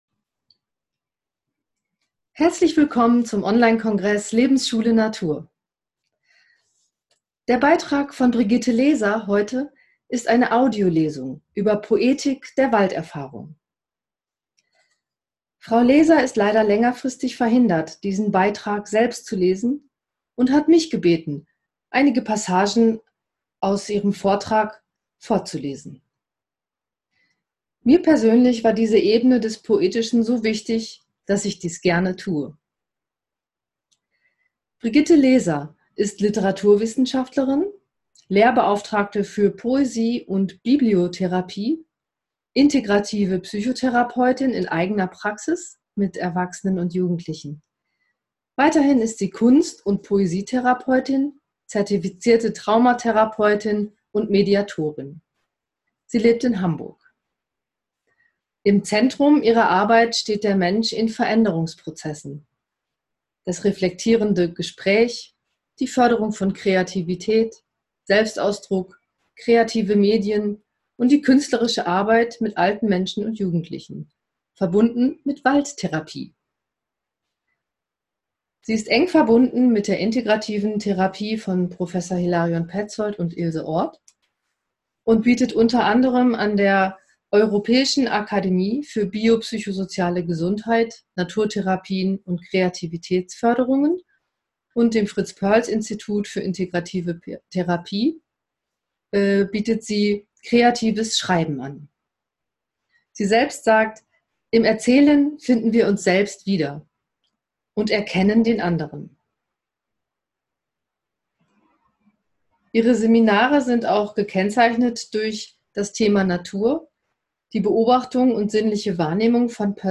Als ich während des Kongresses nun so viele begeisterte, berührte und dankbare Nachrichten von Euch erhielt, kamen mir die Idee und der Wunsch, ein „Dankeschön“ zurückzugeben und einfach jedem von Euch diese Lesung dauerhaft zur Verfügung zu stellen.